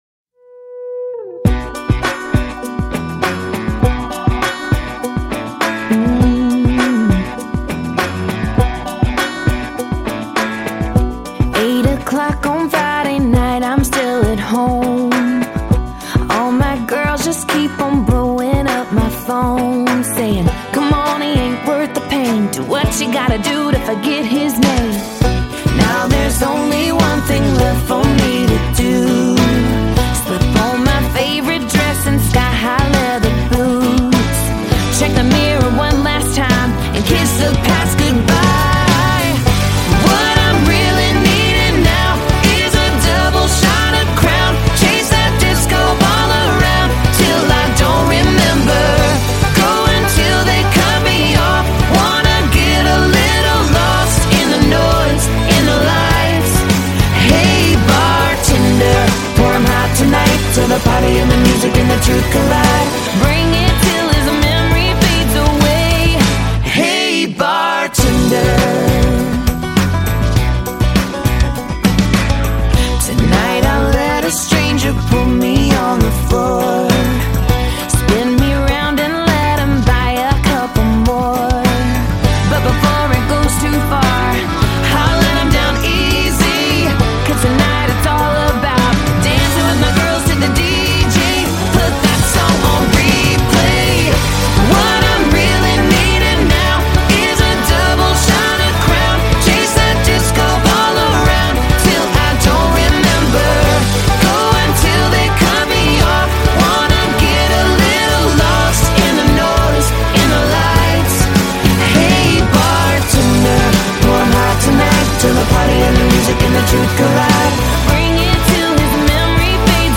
Жанр: Folk